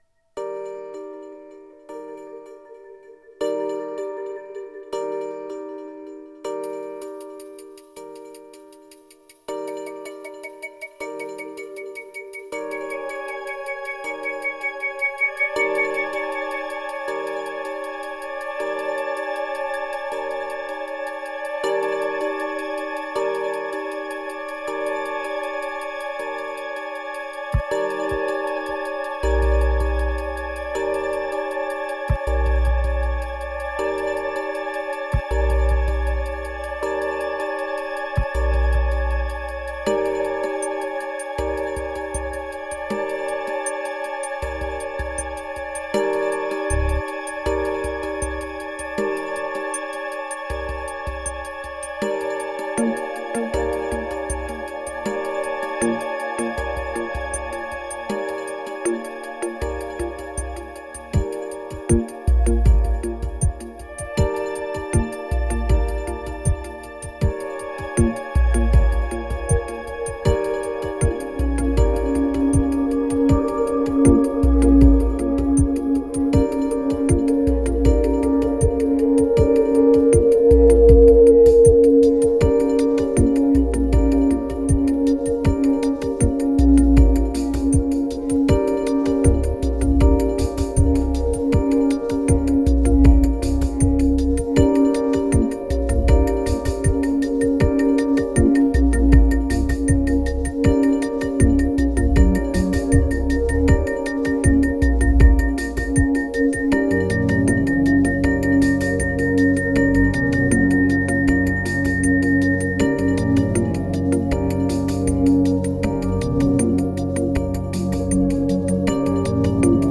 2145📈 - 11%🤔 - 79BPM🔊 - 2015-02-16📅 - -145🌟